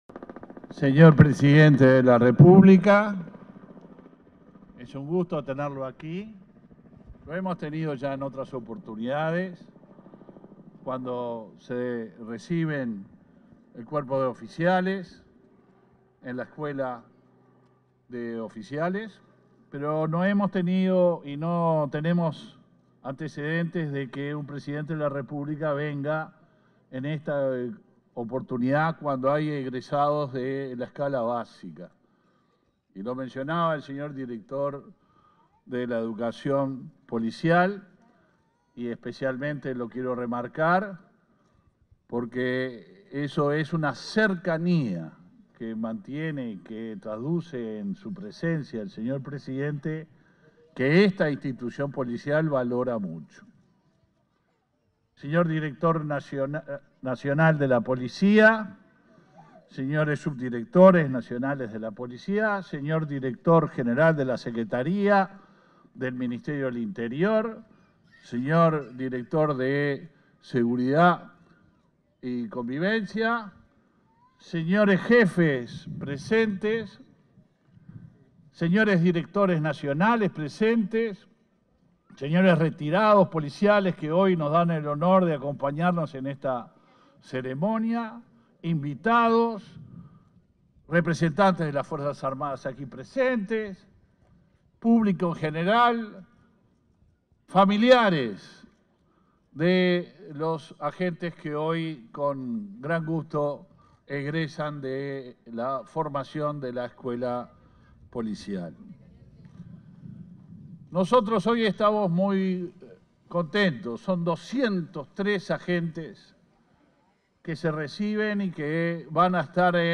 Palabras del ministro del Interior, Luis Alberto Heber
Palabras del ministro del Interior, Luis Alberto Heber 30/03/2023 Compartir Facebook X Copiar enlace WhatsApp LinkedIn En el marco de la ceremonia de egreso de la promoción LXVIII y el primer curso básico de Rescatista Policial, este 30 de marzo, se expresó el ministro del Interior, Luis Alberto Heber.